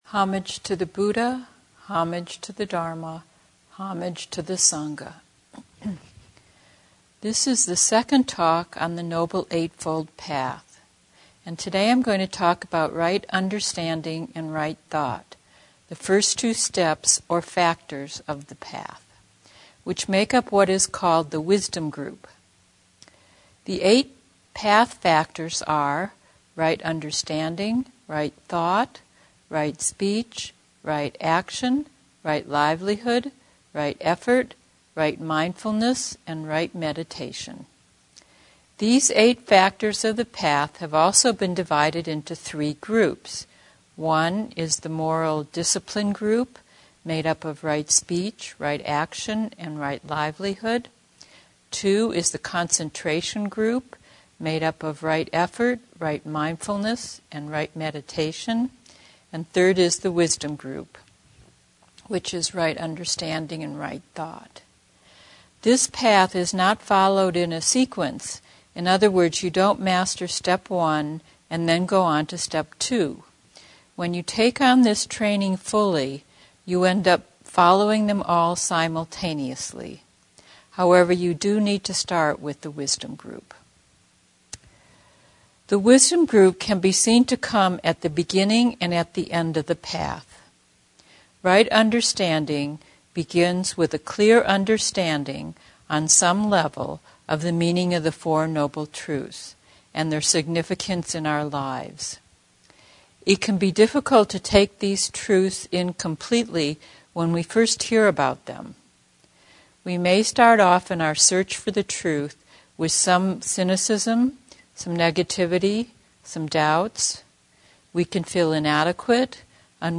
The following series of Dharma talks have been offered by Senior Teachers of Buddhism at retreats or during the Bear River Meditation Group’s regular weekly meetings.